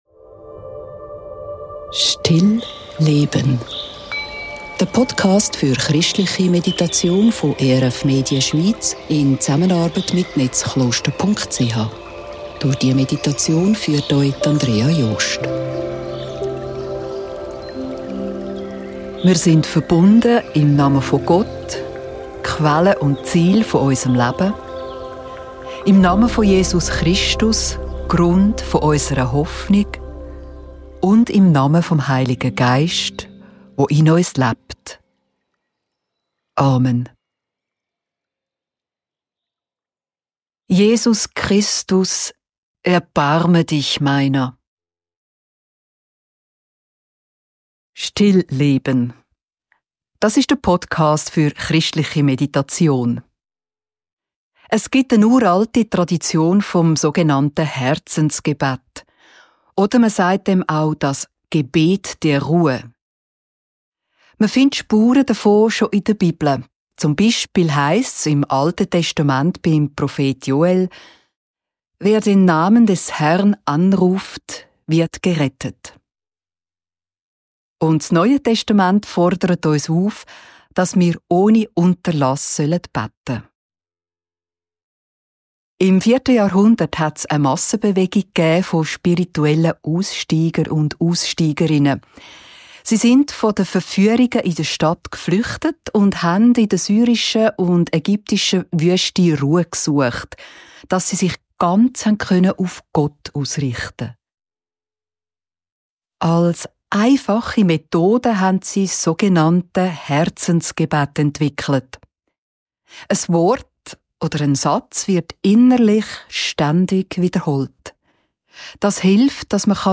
Eine Meditation zum "Herzensgebet"